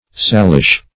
salish - definition of salish - synonyms, pronunciation, spelling from Free Dictionary